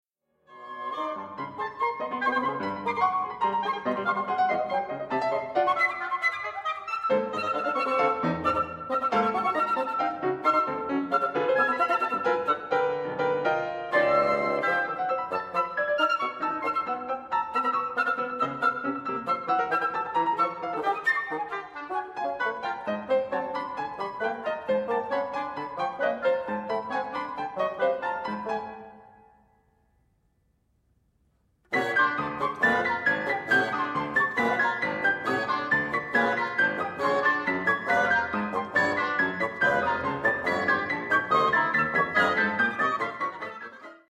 for flute, oboe, bassoon and piano